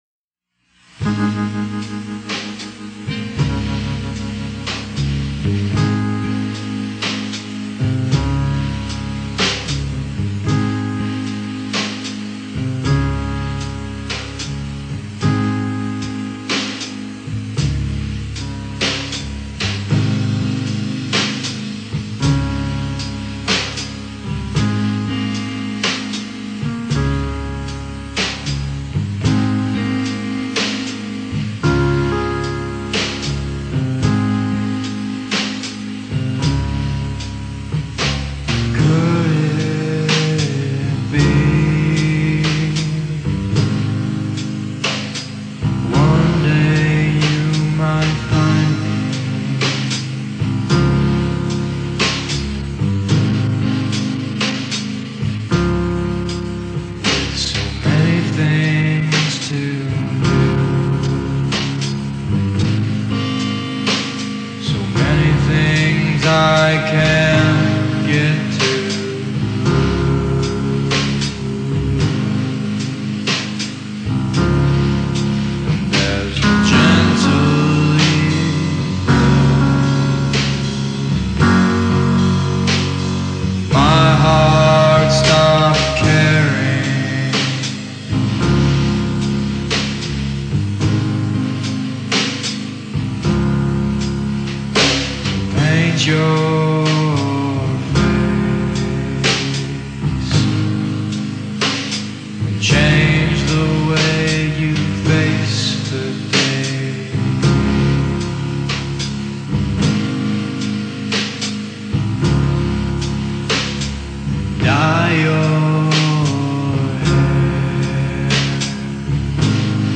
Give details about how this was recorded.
recorded on Four-Track in the living room